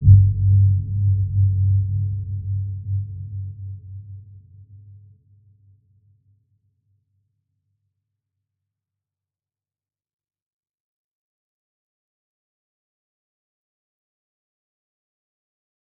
Dark-Soft-Impact-G2-mf.wav